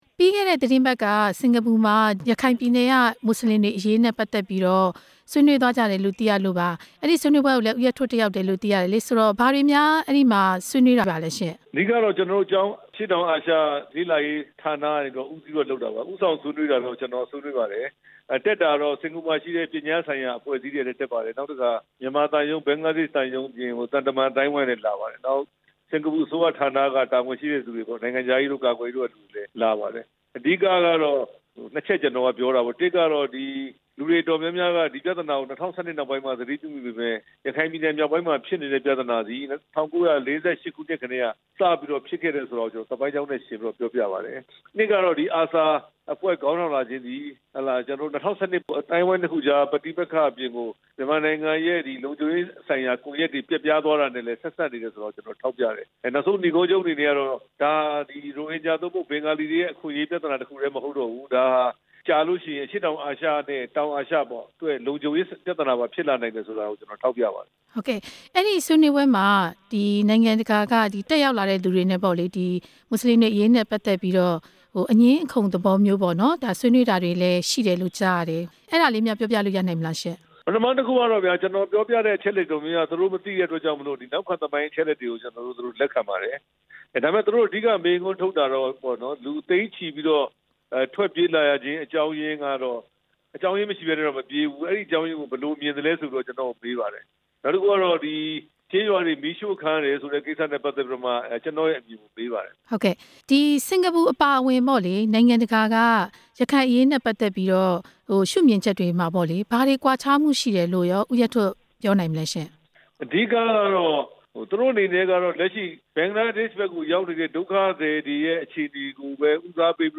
ရခိုင်အရေးဆွေးနွေးပွဲ ဝန်ကြီးဟောင်း ဦးရဲထွဋ် နဲ့ မေးမြန်းချက်